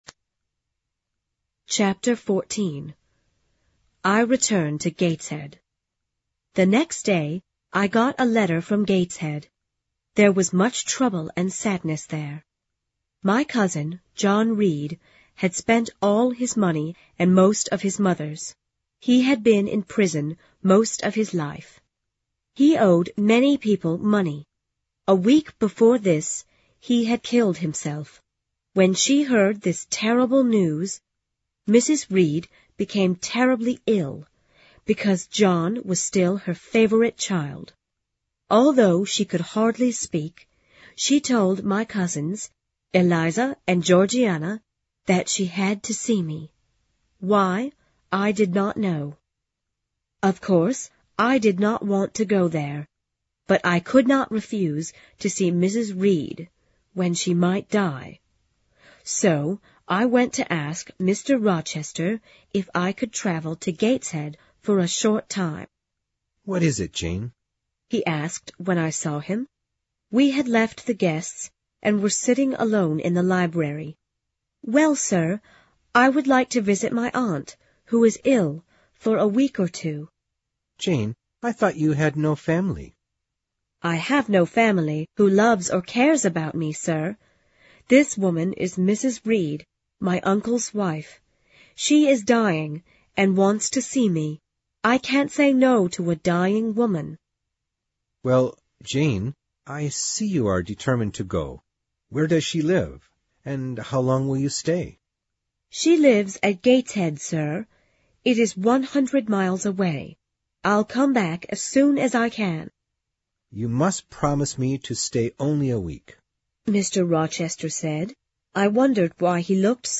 有声名著之简爱Jene Eyer Chapter14 听力文件下载—在线英语听力室